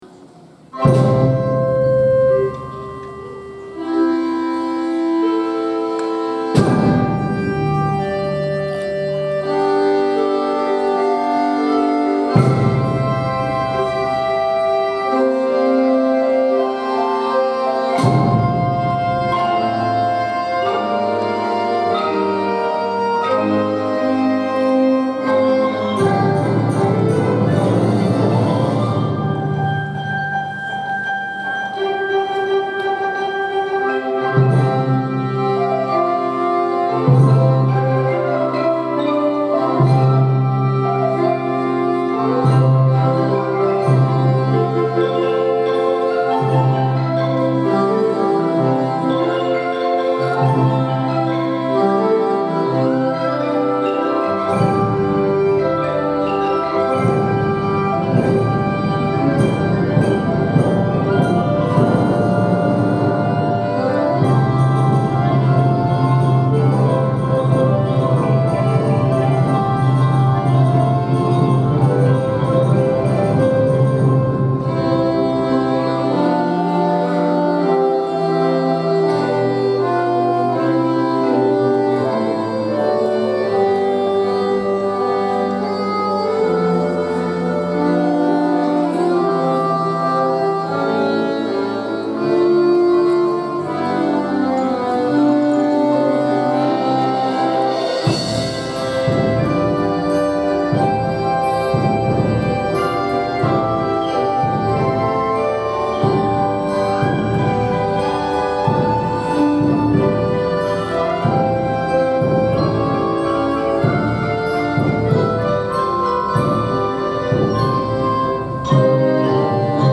３曲のメドレーにチャレンジしました。
１曲目の「ベートーベン交響曲第７番」は、ゆったりとしたテンポから軽快なリズムへと変化していく曲で、それぞれのパートの掛け合いがとても難しい曲でしたが、楽しい曲です。
もう一度演奏することができ、会場中に迫力の演奏を届けました！